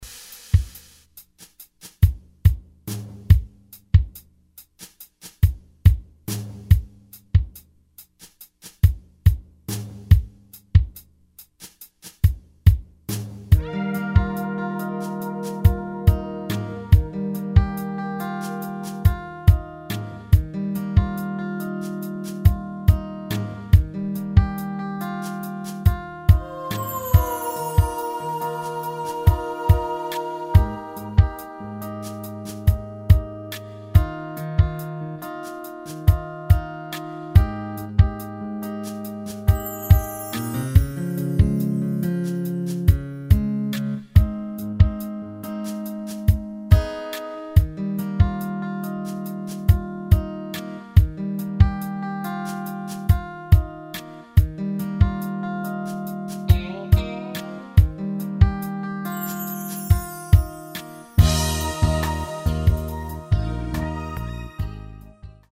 Key of E flat
Backing track only.